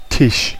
Ääntäminen
Synonyymit pastorat Ääntäminen Tuntematon aksentti: IPA: buːɖ Haettu sana löytyi näillä lähdekielillä: ruotsi Käännös Ääninäyte Substantiivit 1.